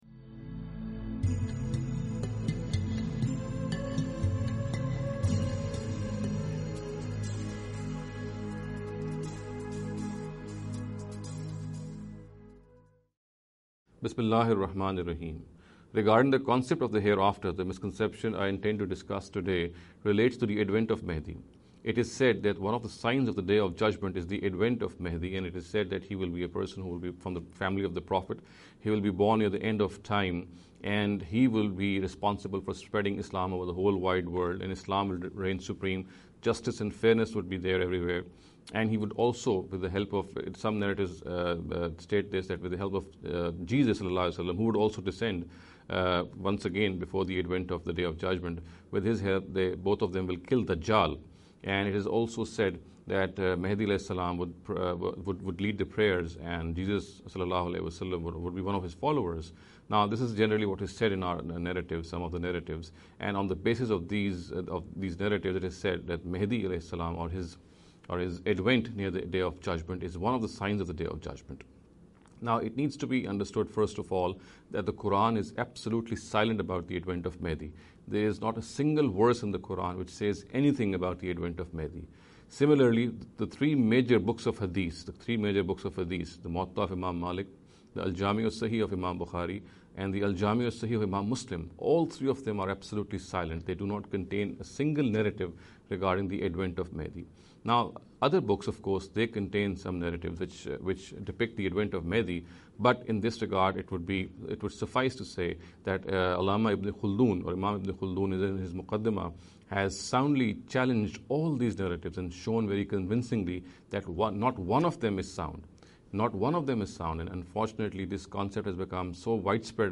This lecture series will deal with some misconception regarding the Concept of the Hereafter.